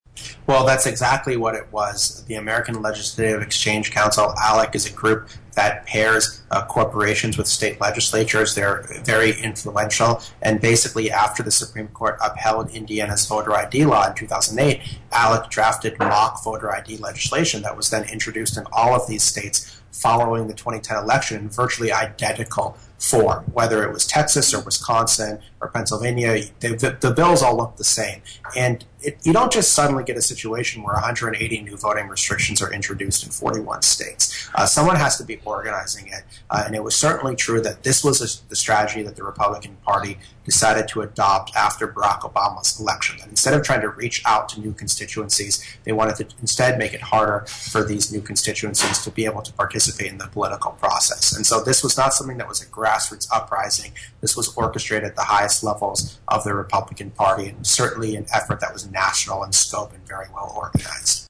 In-Depth Interview: Journalist/Author Ari Berman Details GOP Voter Suppression Campaign